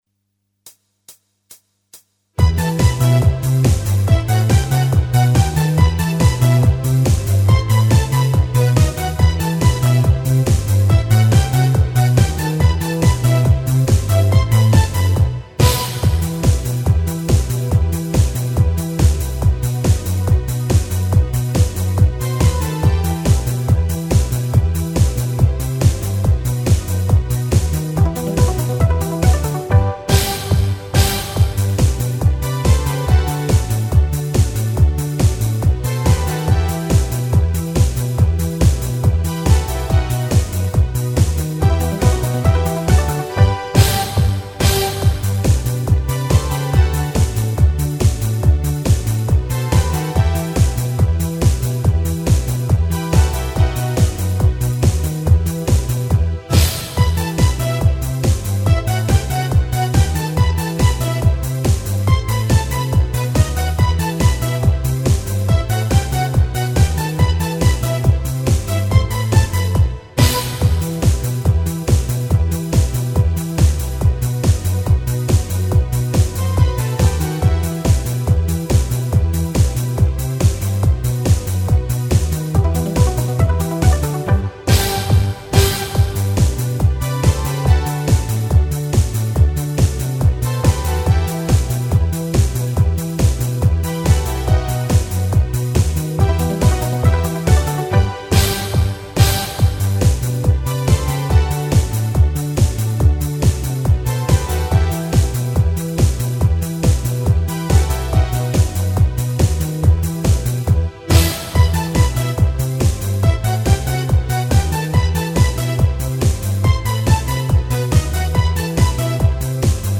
Якість не дуже але гатити можна! ))